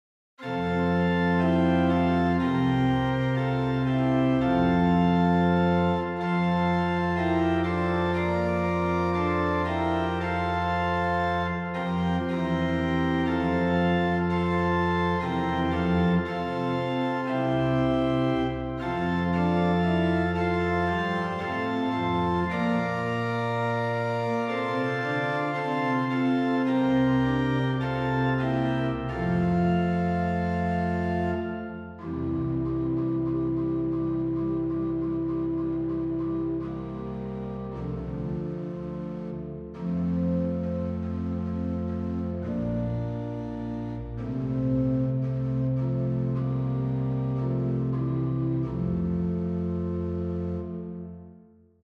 Posnetki z(+) in brez uvoda
+ drugi del moški